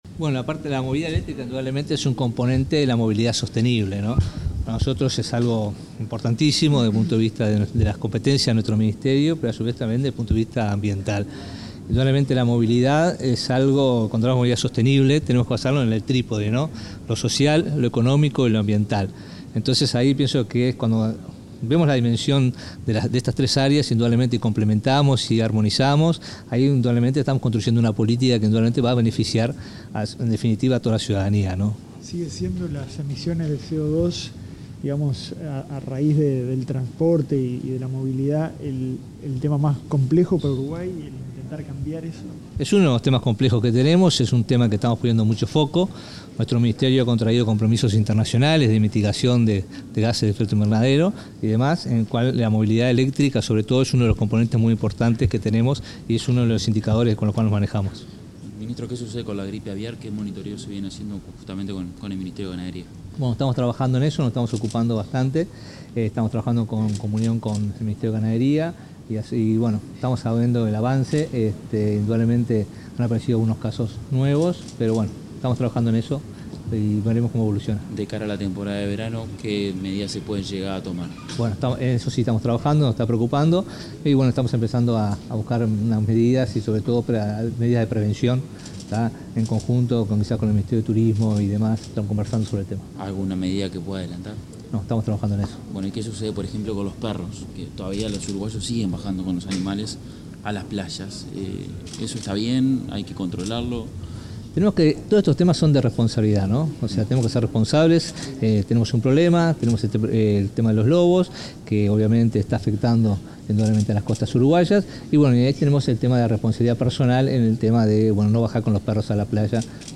Declaraciones del ministro de Ambiente, Robert Bouvier
Declaraciones del ministro de Ambiente, Robert Bouvier 28/09/2023 Compartir Facebook X Copiar enlace WhatsApp LinkedIn El ministro de Ambiente, Robert Bouvier, fue entrevistado por medios periodísticos, este jueves 28 en Montevideo, antes de participar de la apertura del III Foro Internacional de Movilidad Eléctrica.